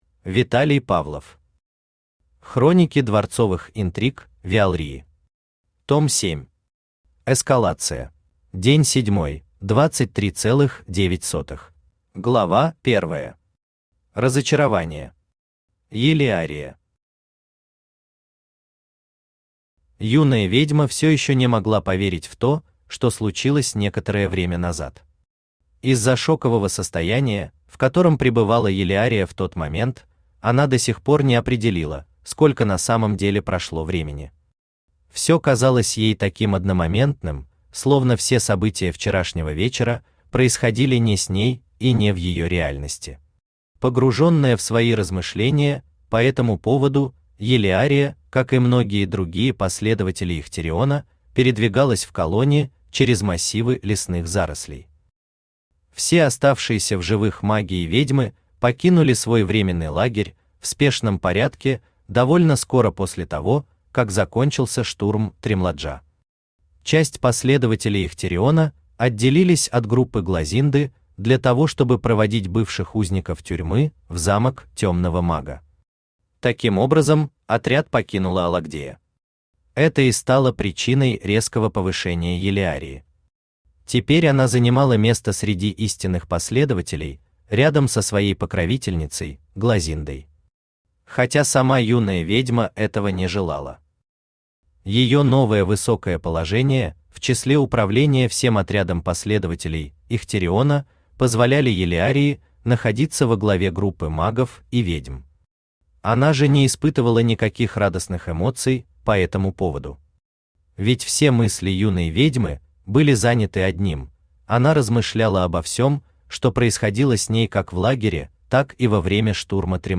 Аудиокнига Хроники дворцовых интриг Виалрии. Том 7. Эскалация | Библиотека аудиокниг
Aудиокнига Хроники дворцовых интриг Виалрии. Том 7. Эскалация Автор Виталий Викторович Павлов Читает аудиокнигу Авточтец ЛитРес.